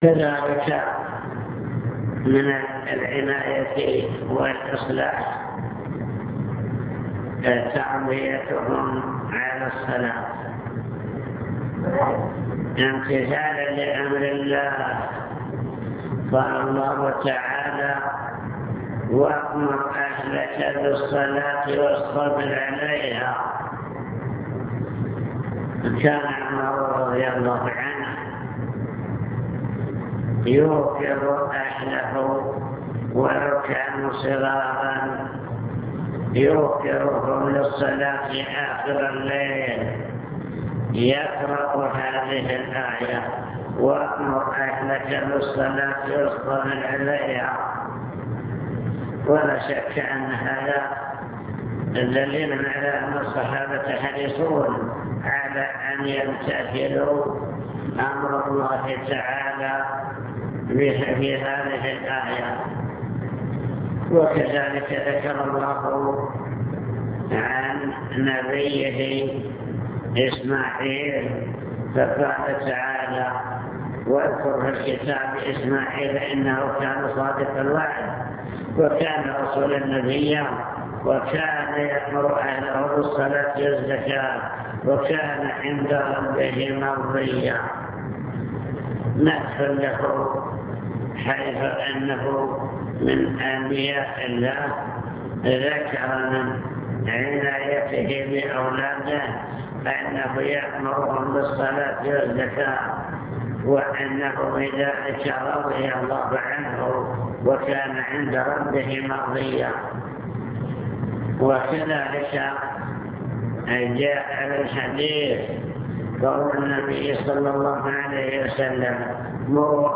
المكتبة الصوتية  تسجيلات - محاضرات ودروس  محاضرة بعنوان توجيهات للأسرة المسلمة طرق وأسباب صلاح الأبناء